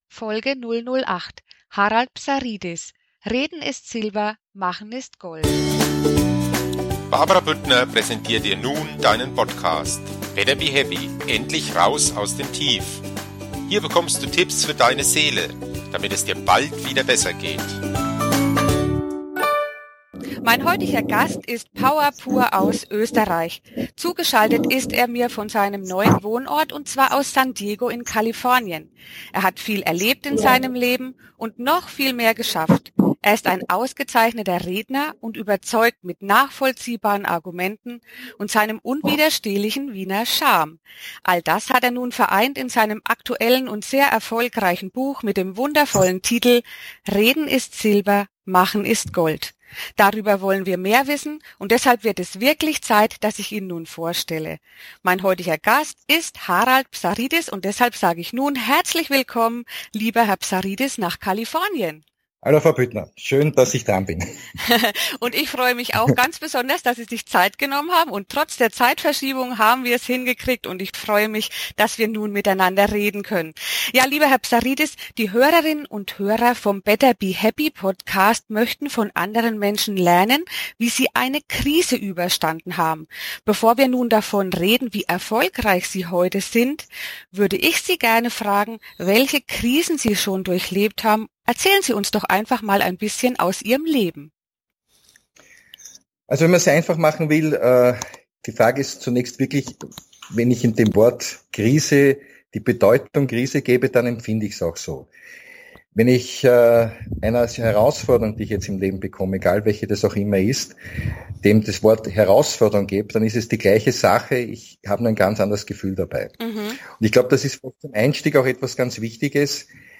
(am Anfang im Interview kleine Tonfehler – dafür sorry!)